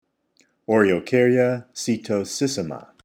Pronunciation/Pronunciación:
O-re-o-cár-ya  se-to-sís-si-ma